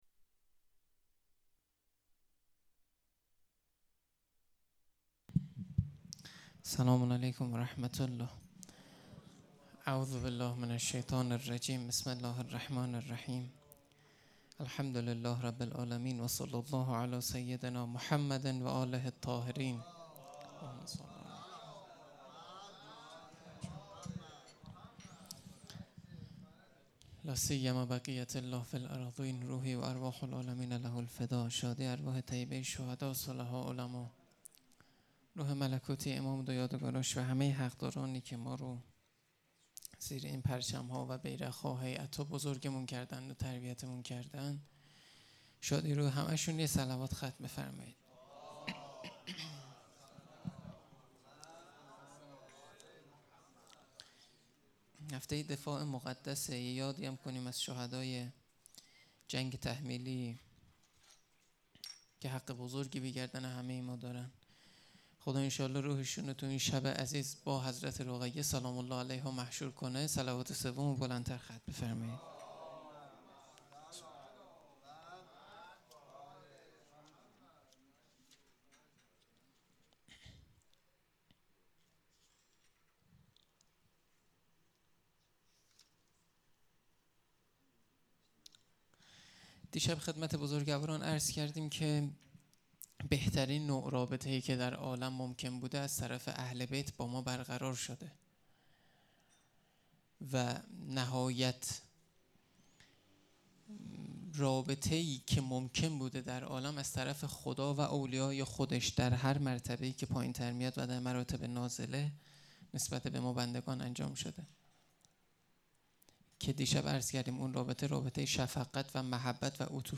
سخنرانی
شب سوم محرم 96